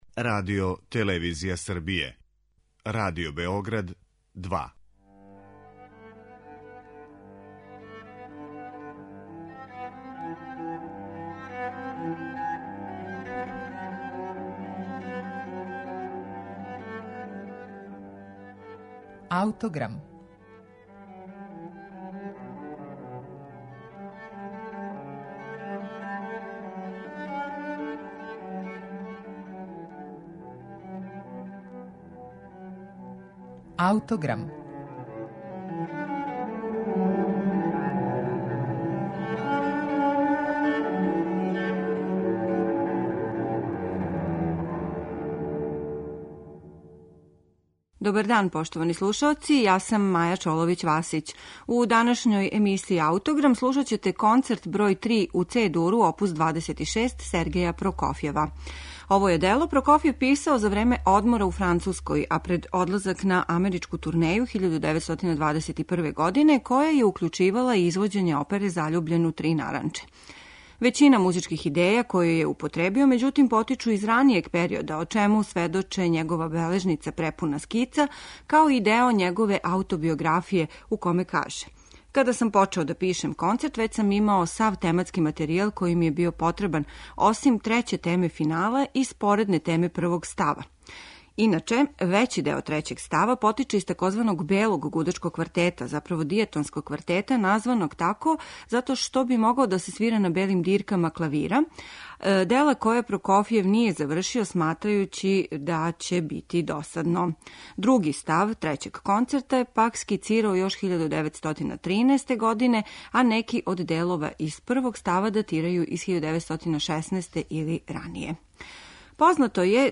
Прокофјев: Трећи клавирски концерт
У данашњем Аутограму, слушаћете III клавирски концерт Сергеја Прокофјева.